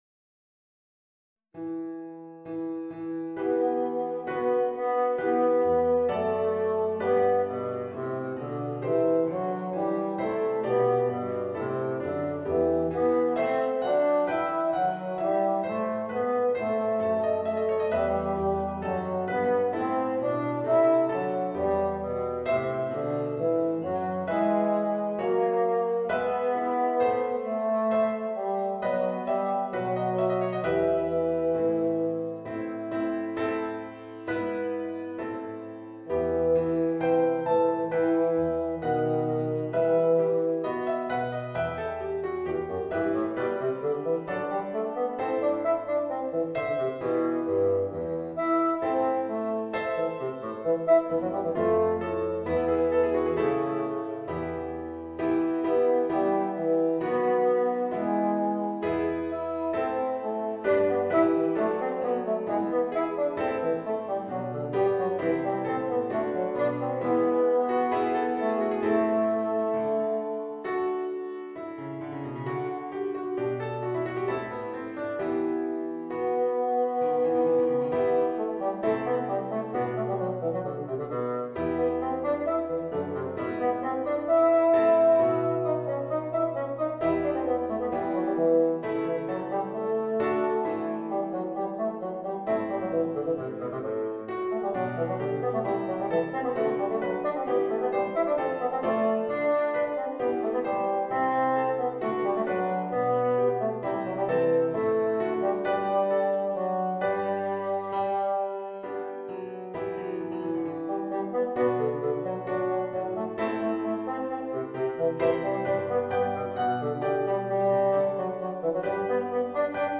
Voicing: Bassoon and Organ